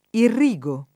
domin. nella tradiz. poetica, oltre che nell’uso corrente, la pn. piana delle forme rizotoniche